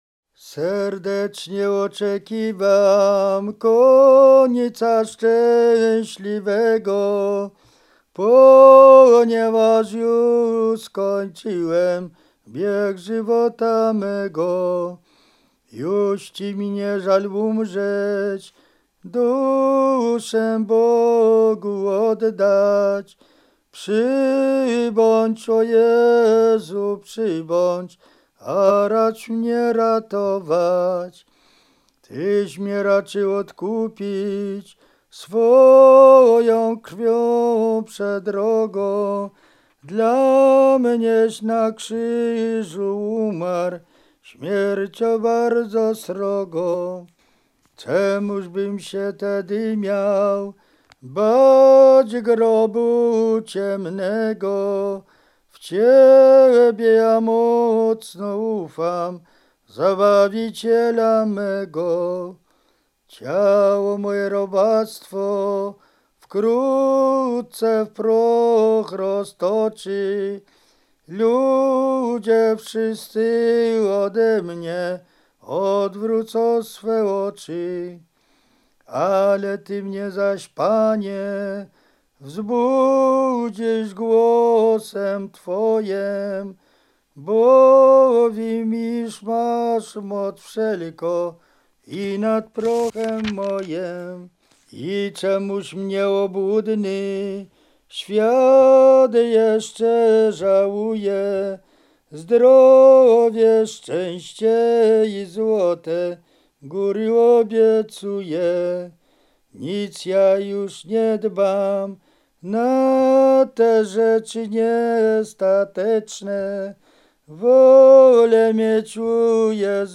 Roztocze
Pogrzebowa
obrzędy domowe pogrzebowe nabożne katolickie